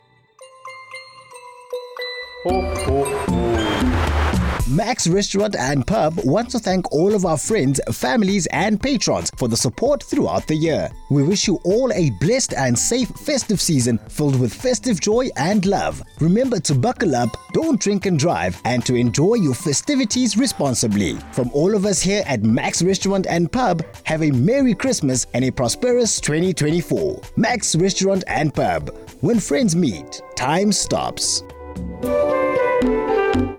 South Africa
confident, dramatic, energetic
Restaurant Festive